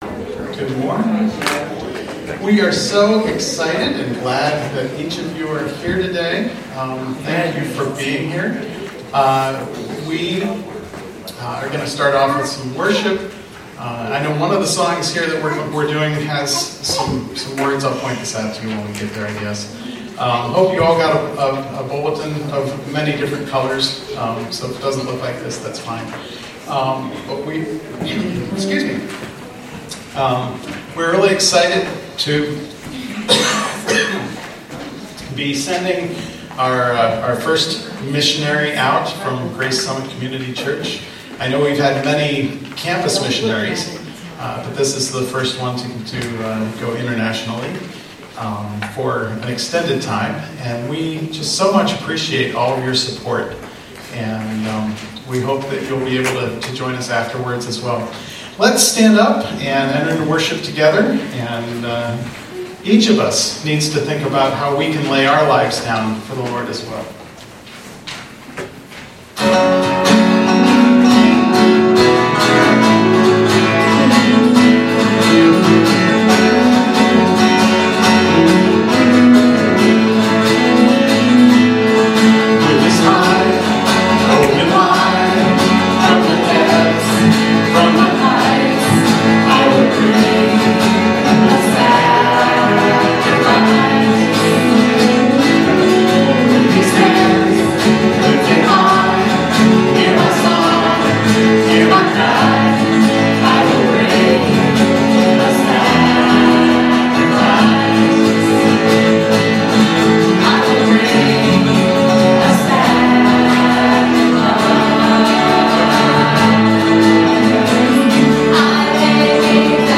Aug 13 2017 Commissioning Service